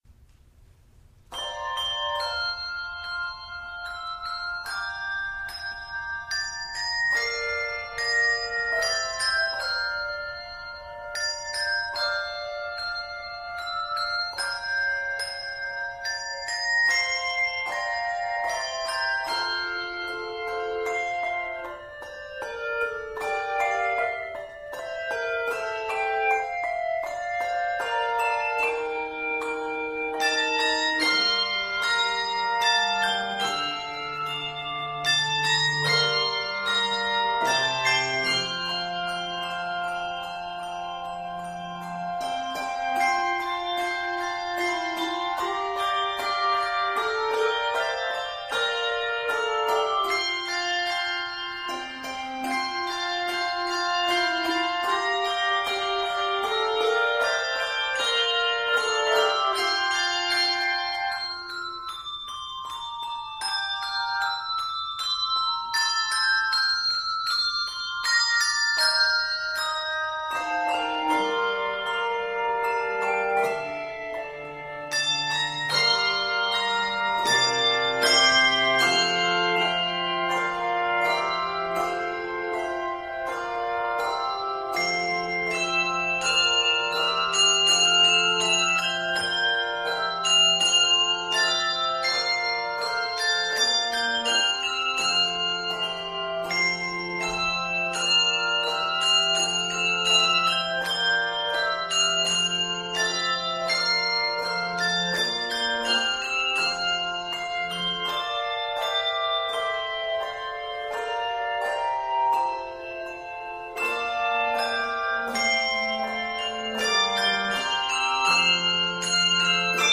Octaves: 3-5
2012 Season: Christmas